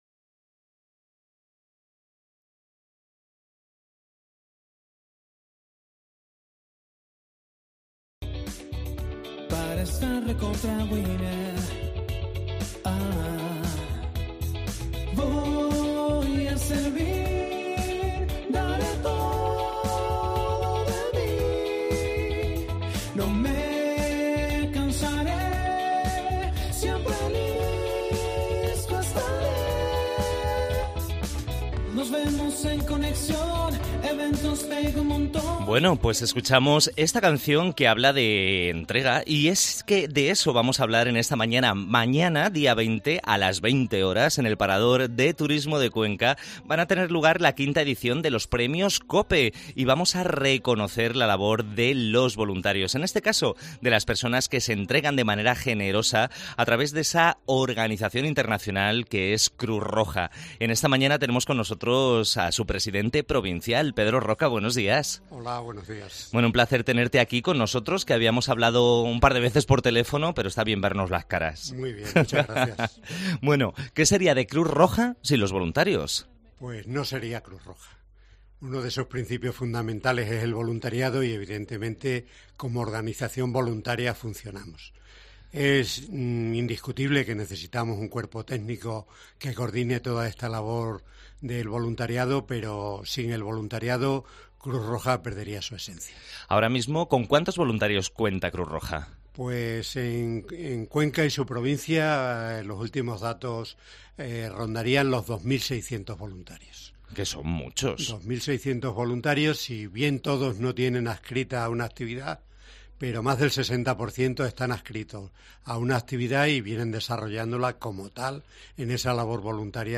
Entevista